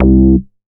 MoogRCA 006.WAV